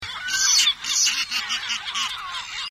Aroharmaalokki / Caspian Gull (Larus cachinnans)